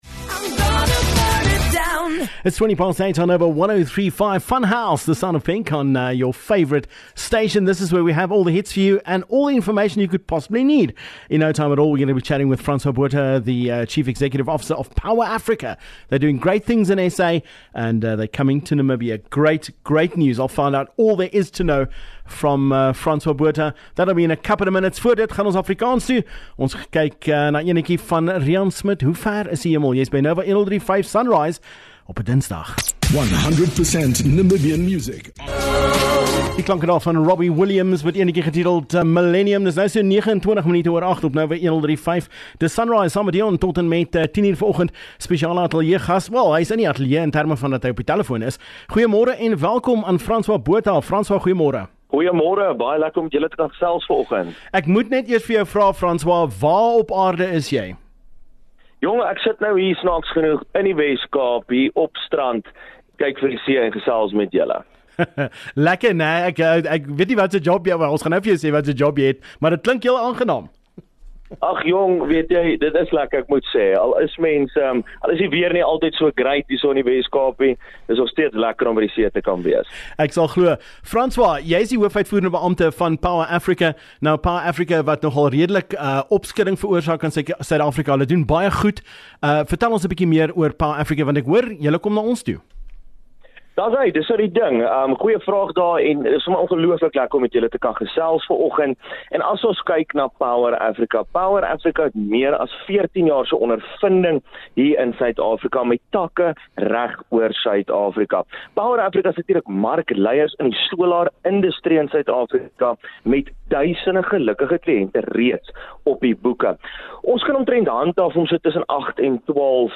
(Interview in Afrikaans!)